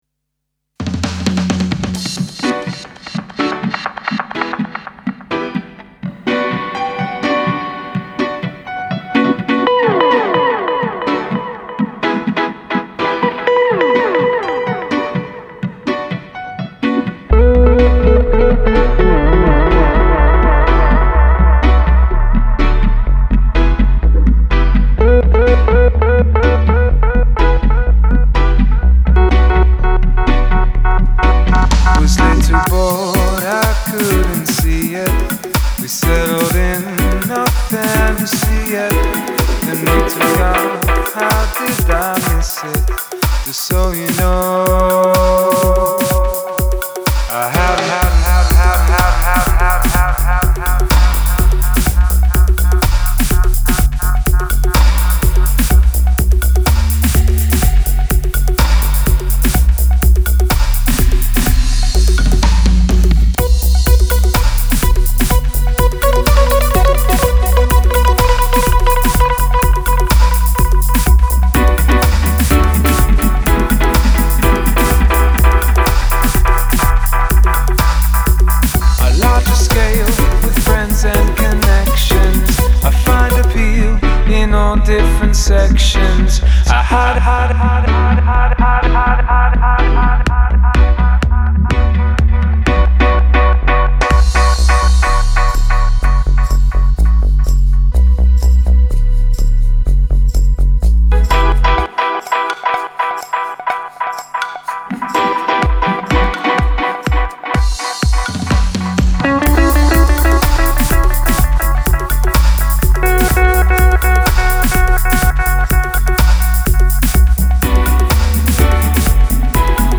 Genre: Dub Reggae